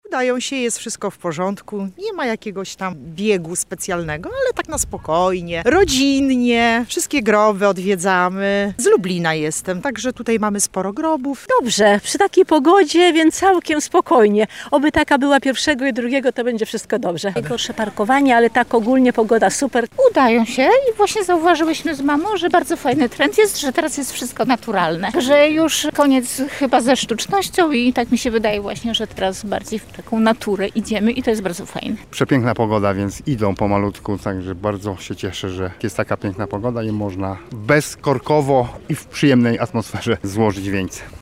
[SONDA] Czy lublinianie i lublinianki są już gotowi na obchody Wszystkich Świętych?
Nasza reporterka pośród chryzantem, wieńców i zniczy spotkała kilku mieszkańców i mieszkanki, których zapytała o to, czy prace przed 1 listopada idą zgodnie z planem: